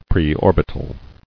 [pre·or·bit·al]